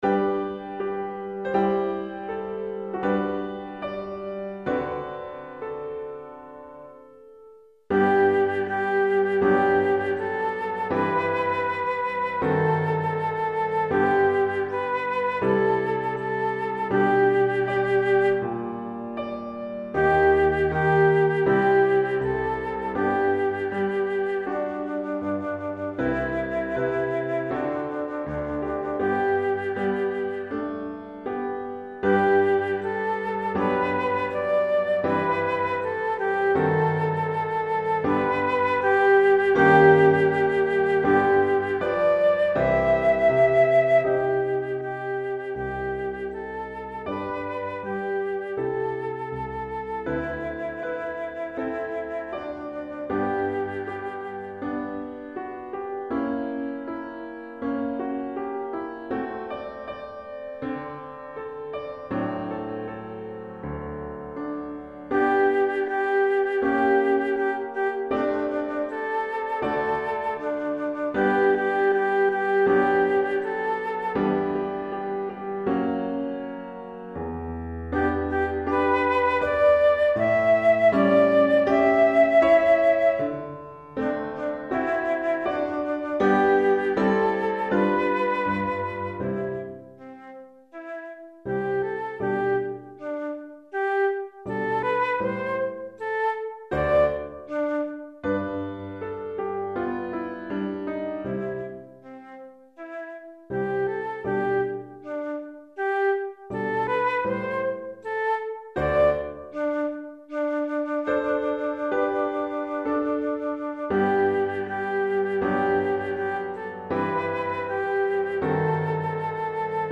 Flûte à Bec et Piano